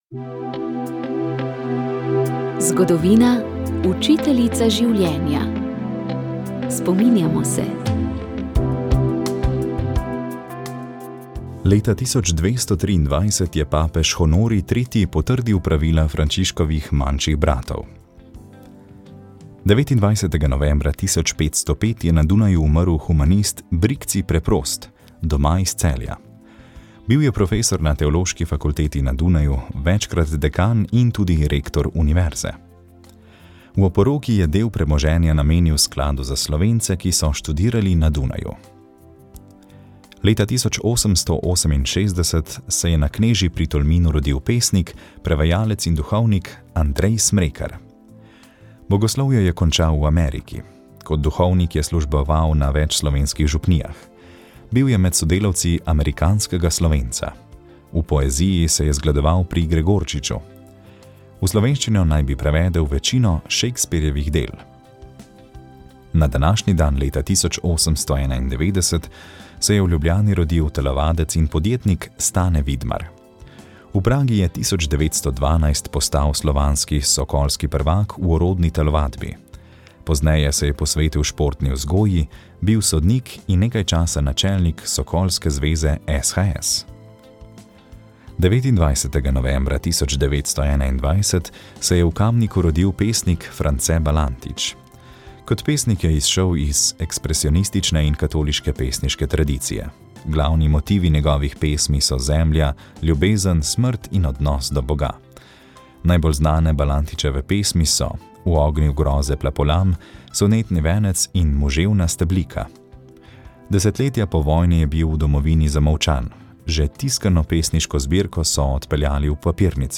Pričevanja, ki jih boste slišali in so bila posneta s strani vosovcev samih, so nastala v letih 1978 in 1979 verjetno kot želja, da se o njihovem delovanju med okupacijo obrani njihova vloga, saj so se v povojnem času začeli pojavljati prvi ugovori proti revolucionarnemu nasilju.